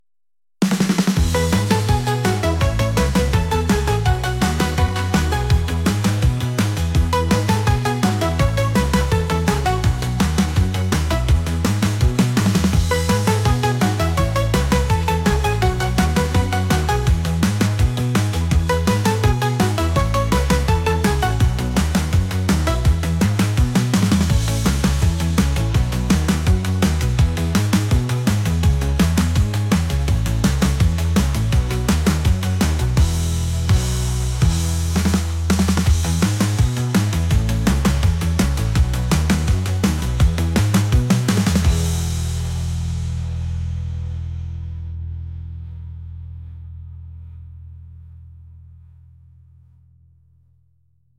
pop | retro | soul & rnb